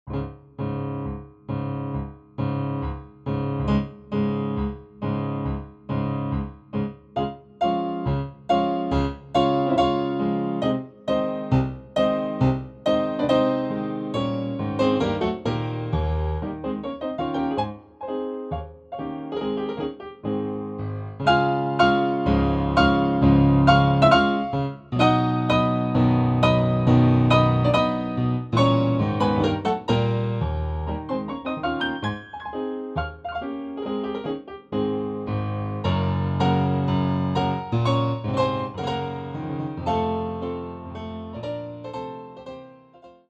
Звучание роялей Shigeru Kawai SK-EX и Kawai EX, воссозданное с помощью технологии формирования звука Harmonic Imaging